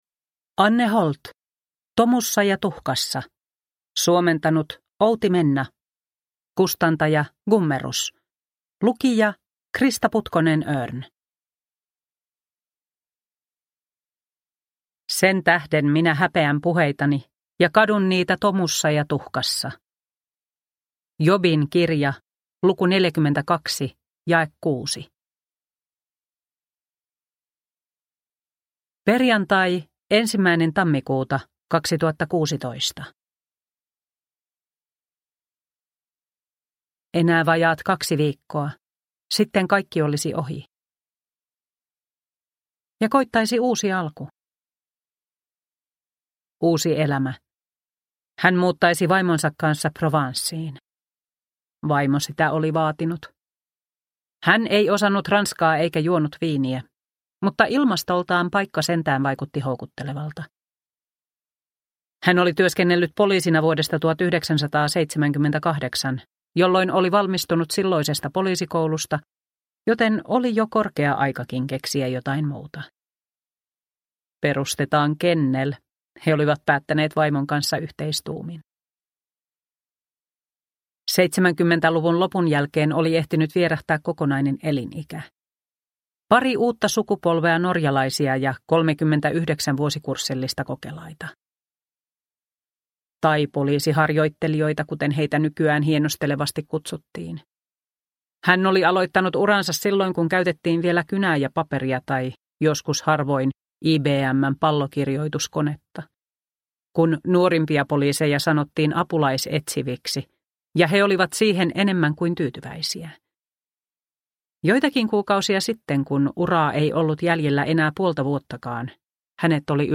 Tomussa ja tuhkassa – Ljudbok – Laddas ner